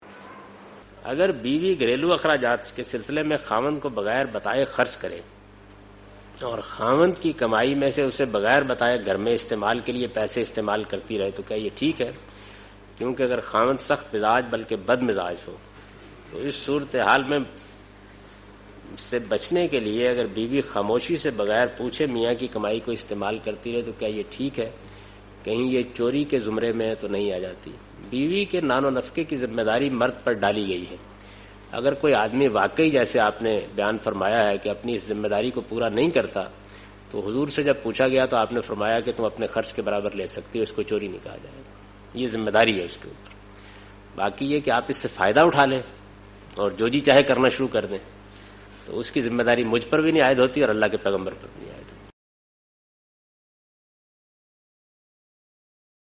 Javed Ahmad Ghamidi responds to the question 'Taking husband money without permission -Is it stealing'?
جاوید احمد غامدی اس سوال کا جواب دے رہے ہیں کہ "اجازت کے بغیر شوہر کے پیسے لینا چوری ہے کیا؟"